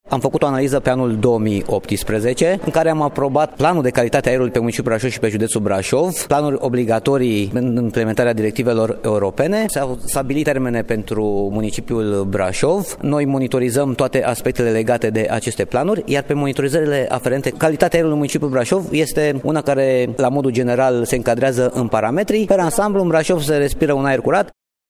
Ciprian Băncilă, director Agenția pentru Protecția Mediului Brașov: